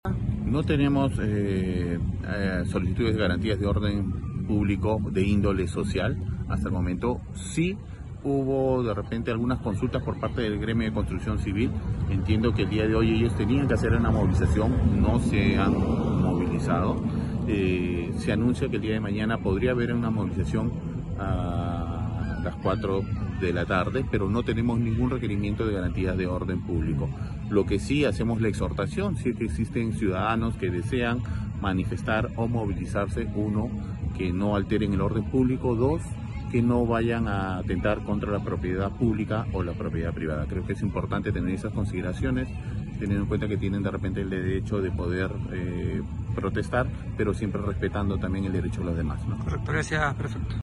07.-PREFECTO-SENALA-QUE-NO-HAY-PEDIDO-GARANTIAS-PARA-MOVILIZACIONES-CONTRA-BOLUARTE.mp3